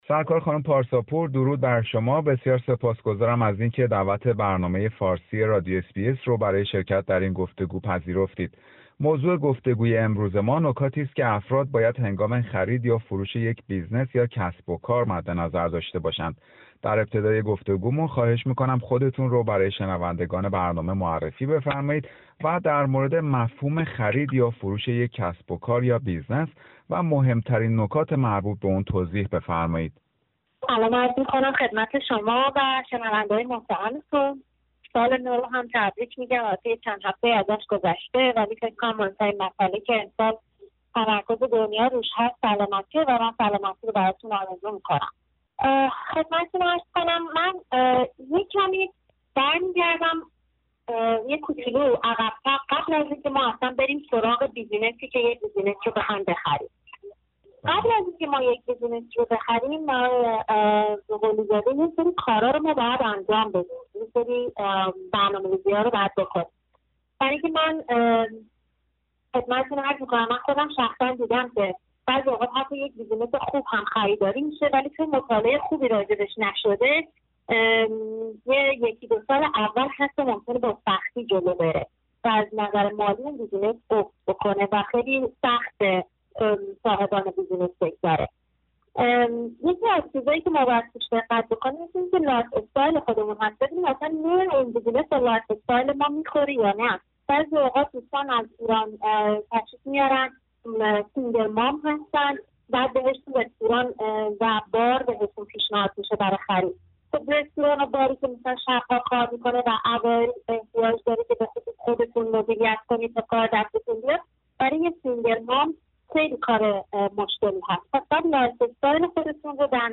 گفتگو; قبل از خرید یا فروش یک بیزنس (کسب و کار) به این نکات و توصیه ها توجه کنید